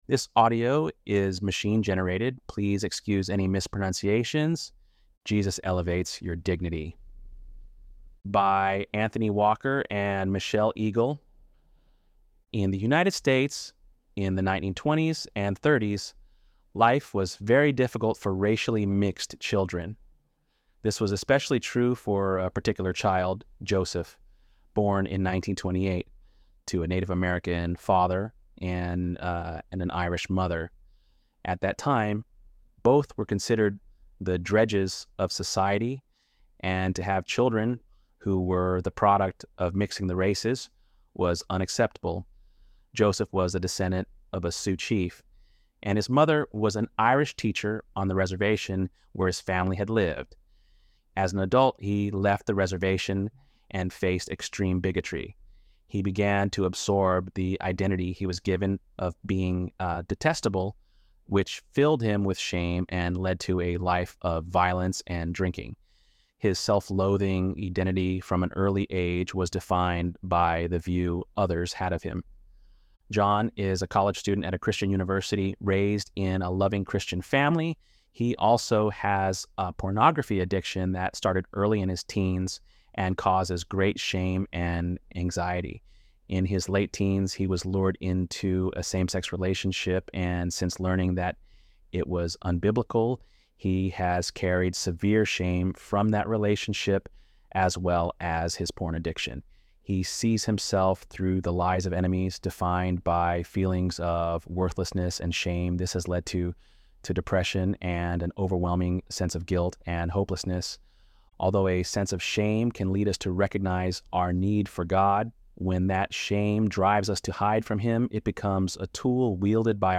ElevenLabs_4.30_Dignity.mp3